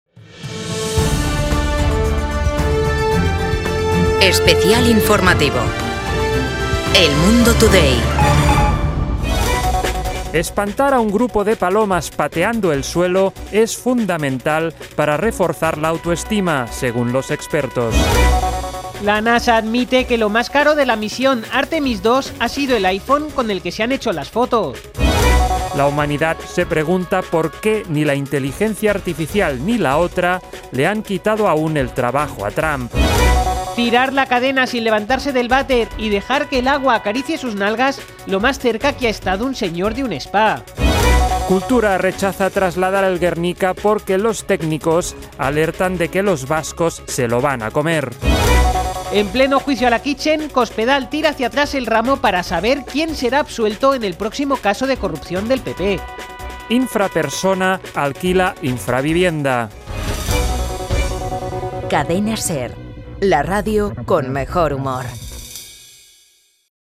Boletín informativo de El Mundo Today | 4:00H